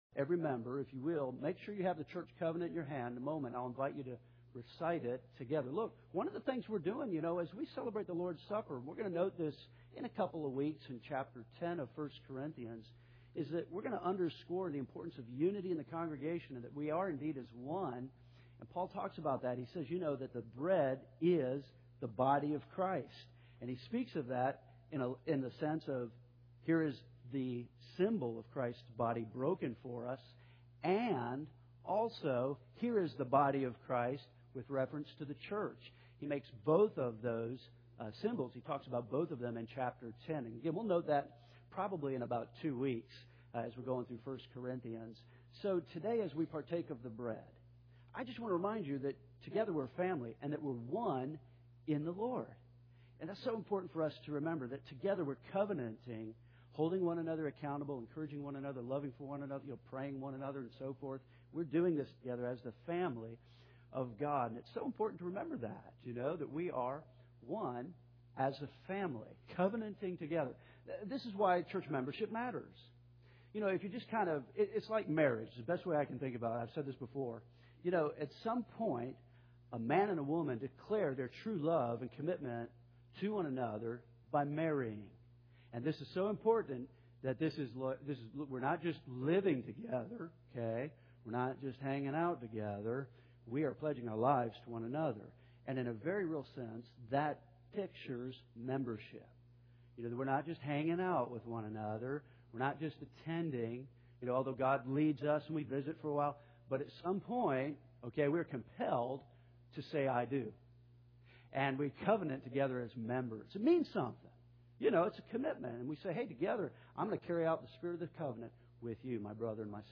We are continuing our verse-by-verse expository study of 1 Corinthians.